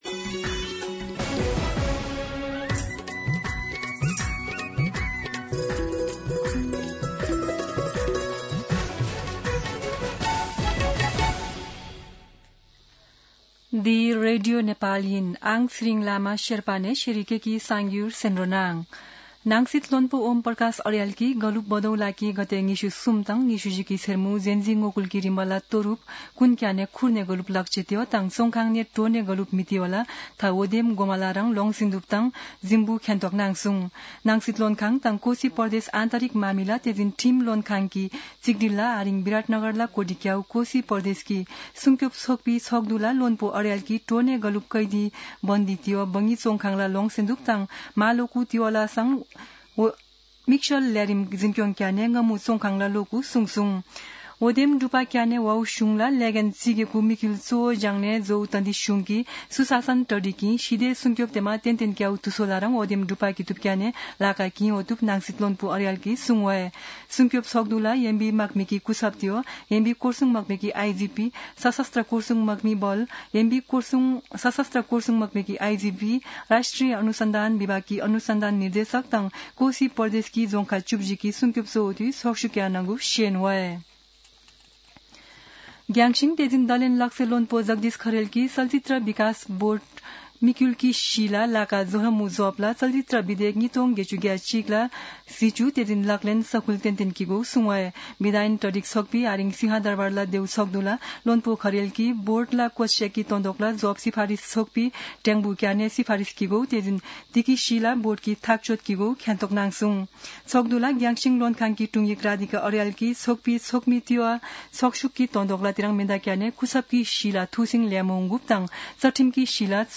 शेर्पा भाषाको समाचार : १६ पुष , २०८२
Sherpa-news-9-16.mp3